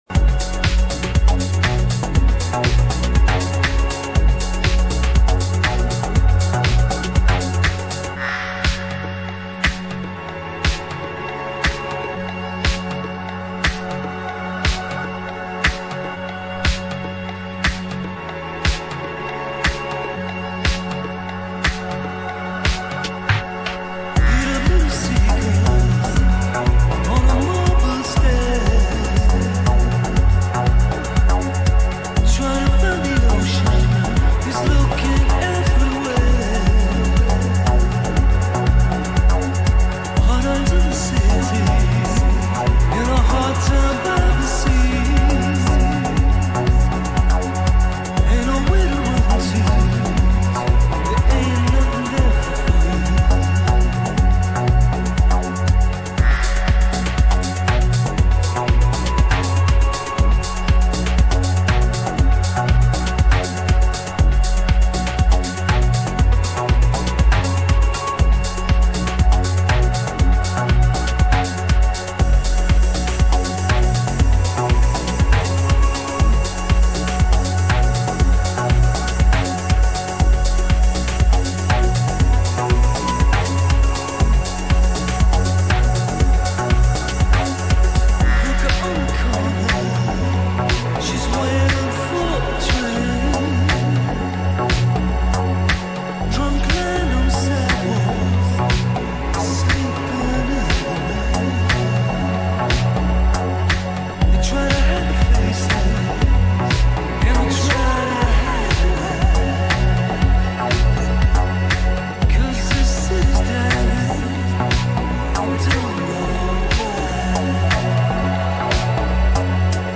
オブスキュア・ディスコ・リエディット・レーベル